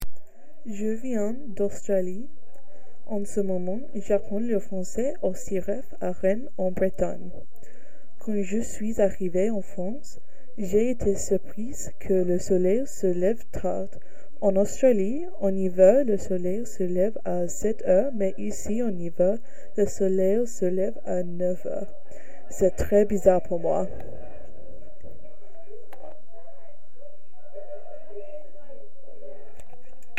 Cabine de témoignages
Témoignage du 24 novembre 2025 à 19h22